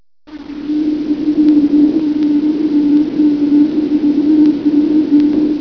Spooky Wind Download
Spookywind.mp3